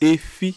[+ATR]
Listen to Ega efi 'eye' efi